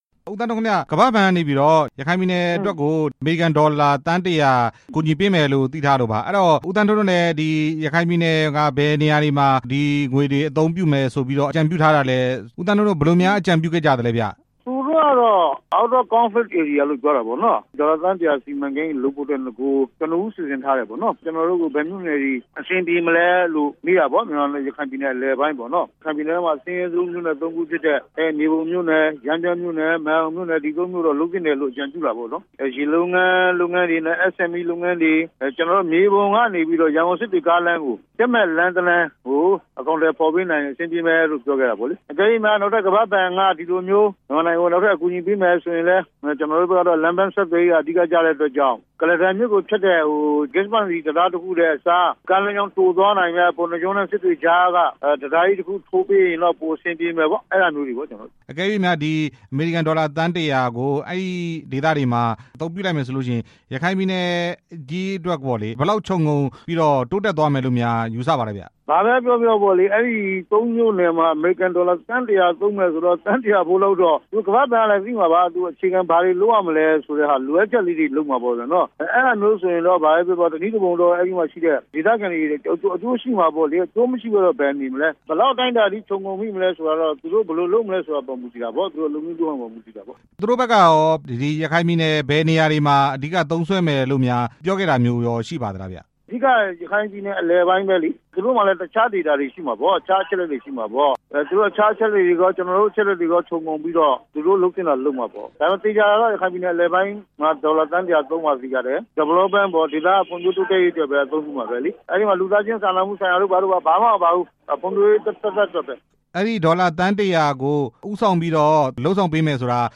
ရခိုင်ပြည်နယ်ဖွံ့ဖြိုးရေးအတွက် ကမ္ဘာ့ဘဏ် ချေးငွေကူညီမယ့်အကြောင်း မေးမြန်းချက်